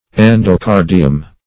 Endocardium \En`do*car"di*um\, n. [NL., fr. Gr.